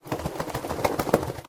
mob / wolf / shake.ogg
shake.ogg